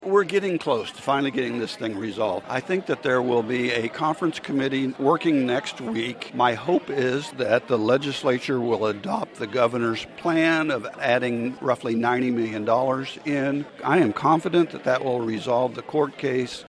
MANHATTAN — The Manhattan Area Chamber of Commerce hosted its second legislative coffee Saturday at Sunset Zoo.
One of the key issues facing legislators continues to be how it addresses funding K-12 education. 67th District Representative Tom Phillips (R-Manhattan) remains optimistic it gets resolved sooner rather than later.